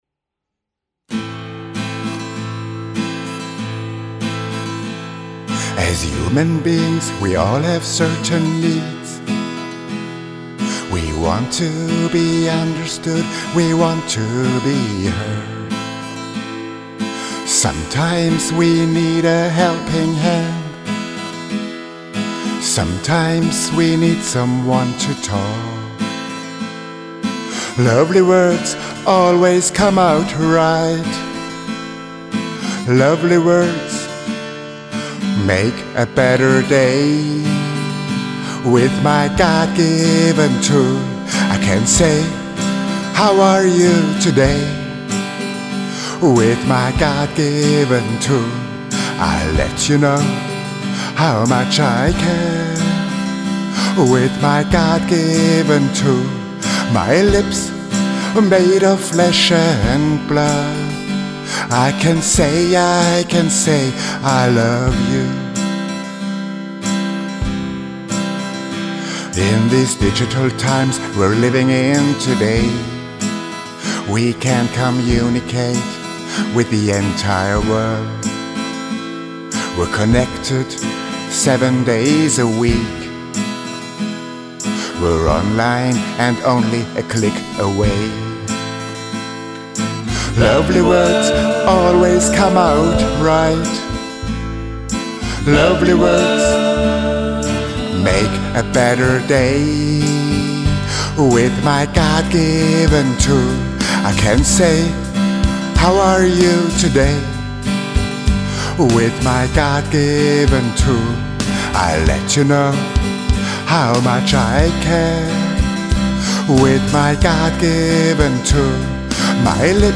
backing vocs